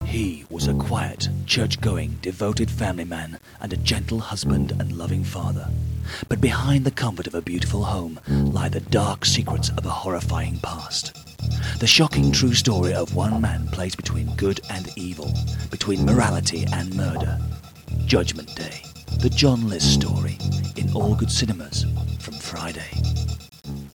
US Voice Overs
American Sampler
Judgement Day – Film Ad